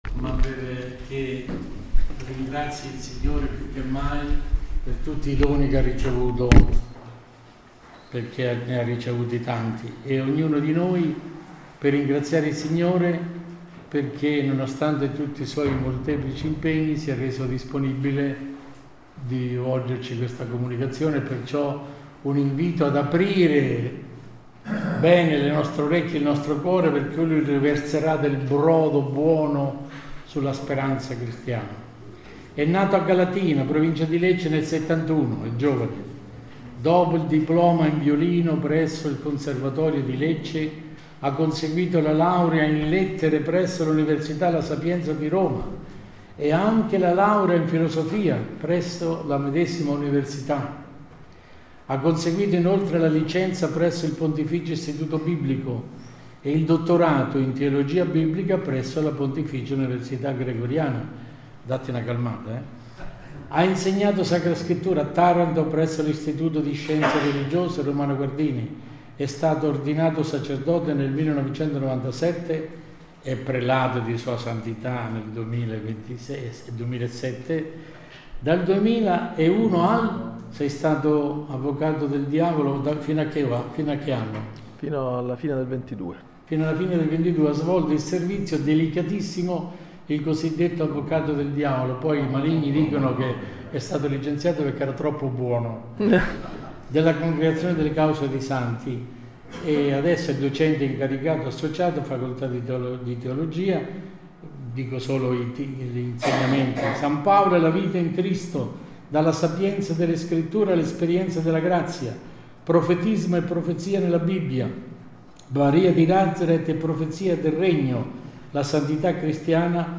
Convegno: “la speranza non delude” – Roma, 18-20 Febbraio 2025 – Associazione "Ancilla Domini"